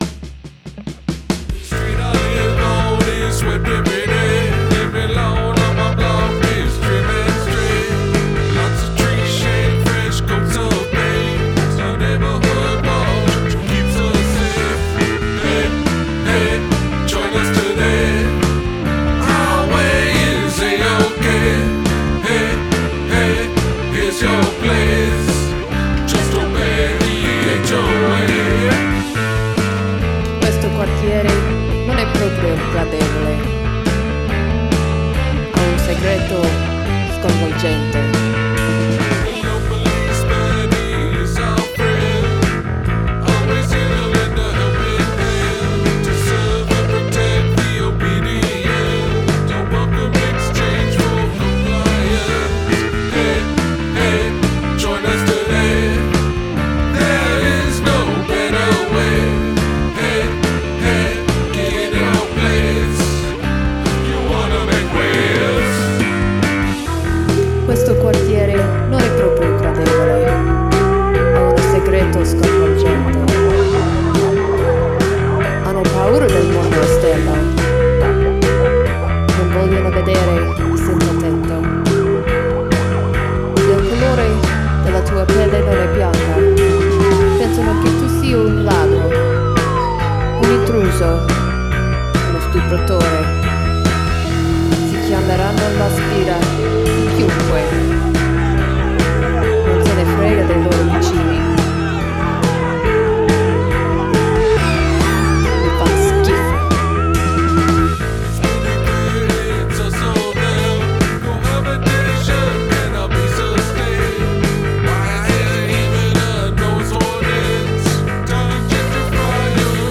Must include a guest singing or speaking in another language
I particularly like the bass sound in this.
The doubled vox works well.